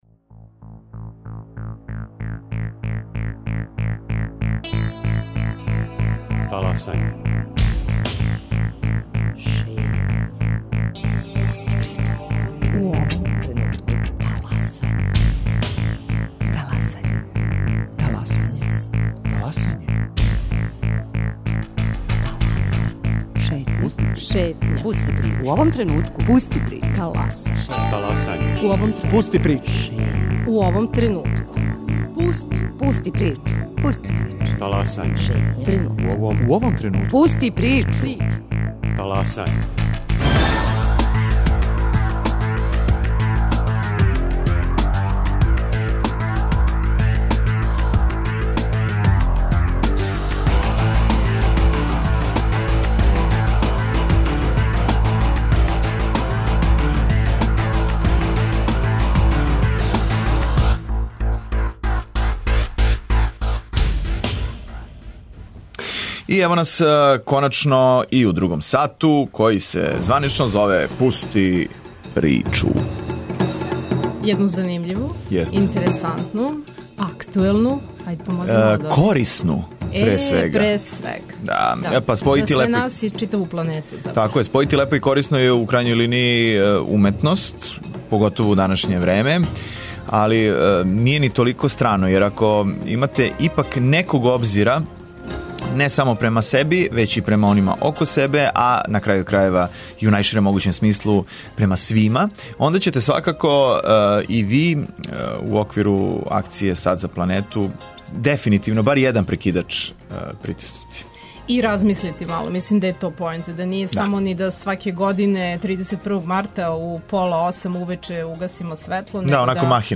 Репортери из читавог света - од Аустралије до Америке, најавиће како ће у њиховим земљама бити организован "Сат за нашу планету" и шта чине по питању престанка загађења животне средине о заустављања последица климатских промена.